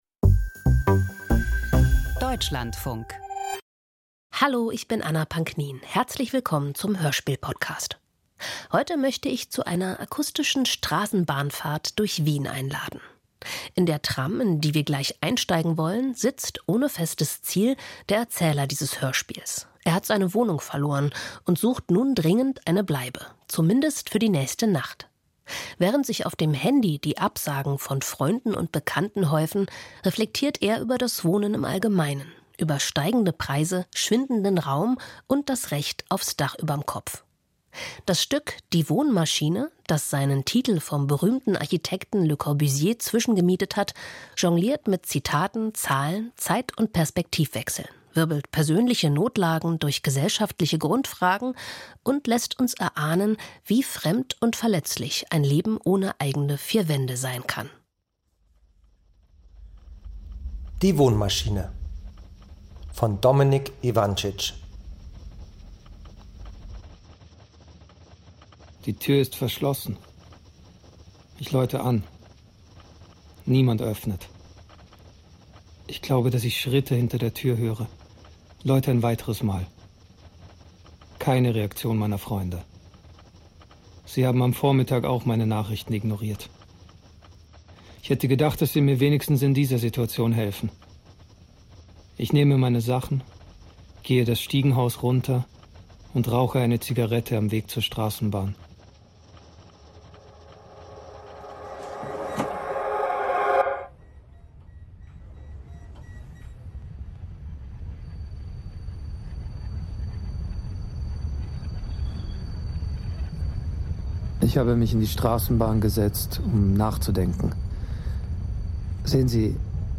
Hörspiel über Wohnungsnot in Wien - Die Wohnmaschine